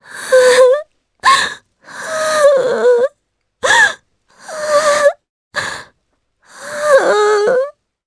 Laias-Vox_Sad_jp.wav